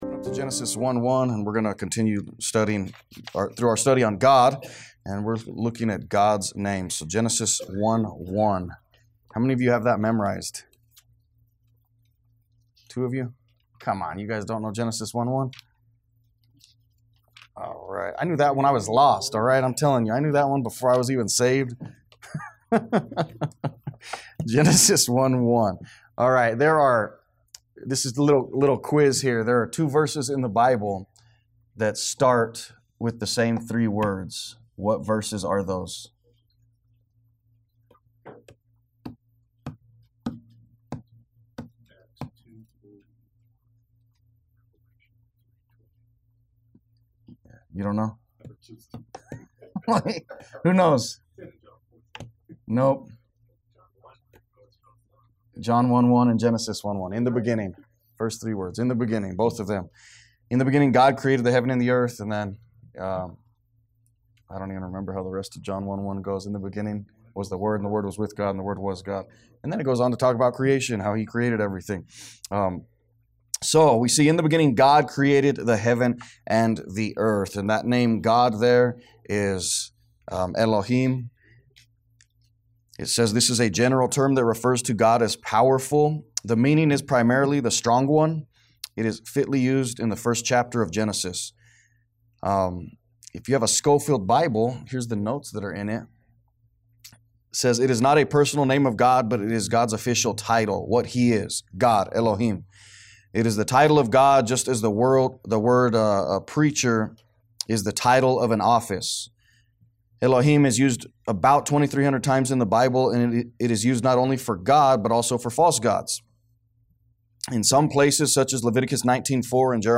A message from the series "Doctrine of The Bible."